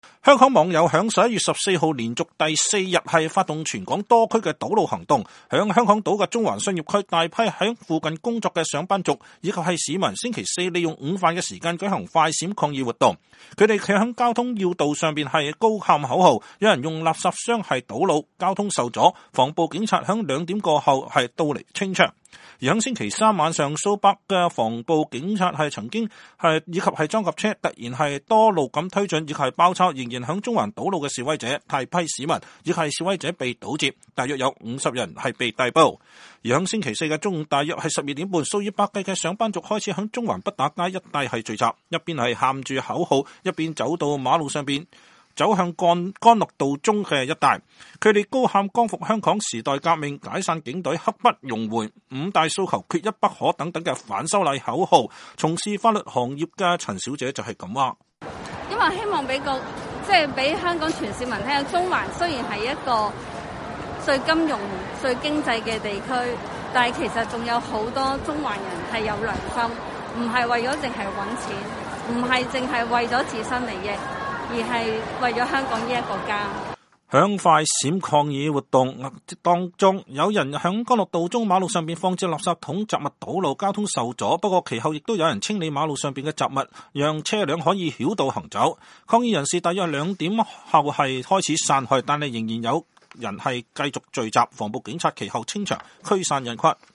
在港島中環商業區，大批在附近工作的“上班族”和市民周四利用午飯時間舉行“快閃”抗議活動。他們站在交通要道上高喊口號，有人用垃圾箱堵路，交通受阻。
中午約12點半，數以百計的“上班族”開始在中環畢打街一帶聚集，一邊喊著口號，一邊走到馬路上，走向幹諾道中一帶。